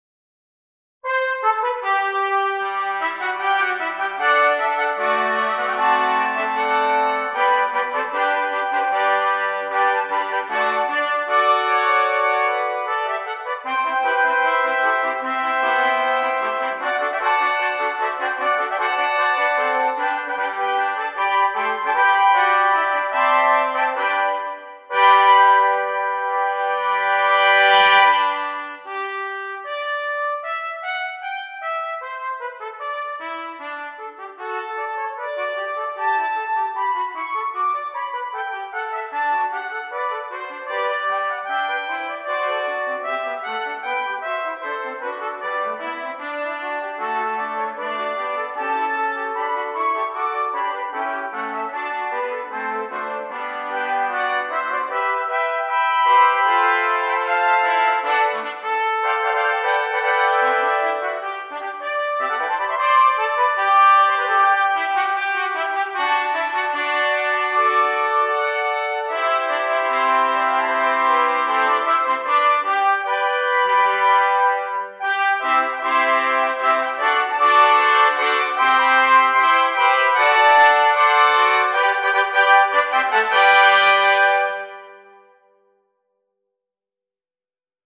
6 Trumpets
This piece works extremely well as an opening crowd grabber.